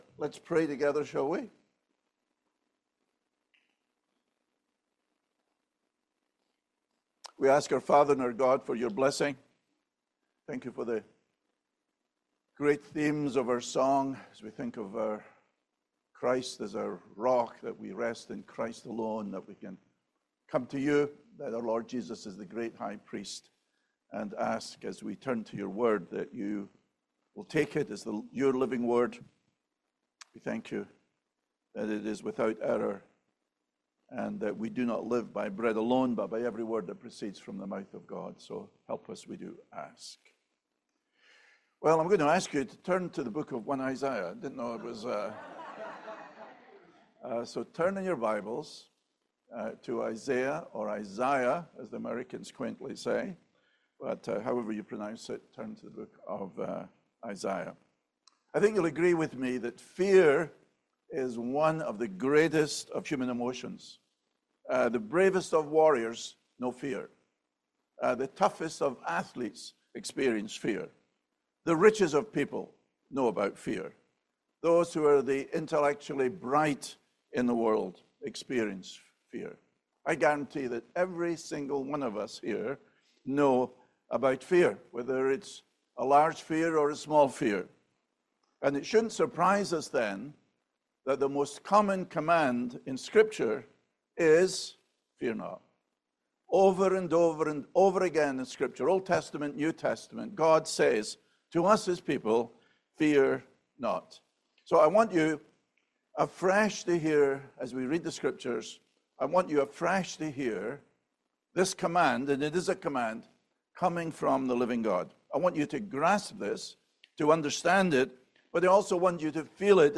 Isaiah 41:10-14 Service Type: Family Bible Hour Replace fear with faith in the sovereign